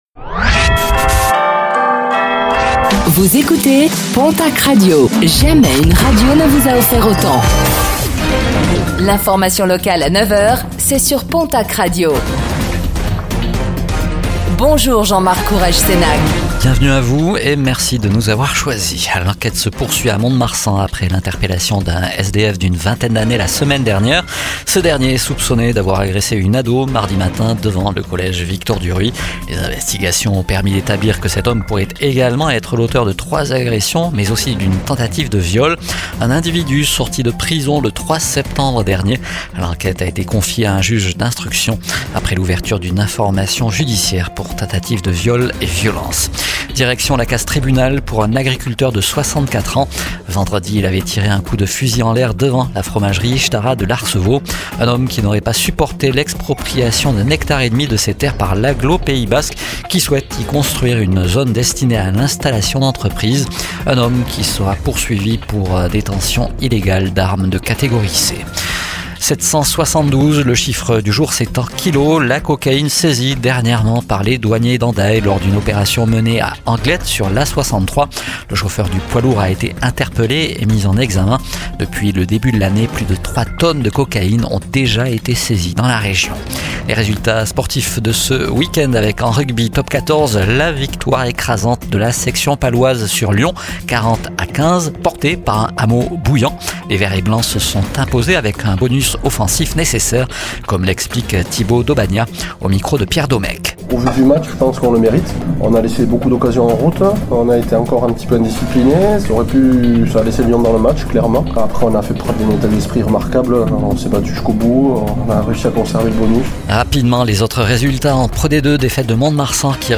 Réécoutez le flash d'information locale de ce lundi 29 septembre 2025 , présenté par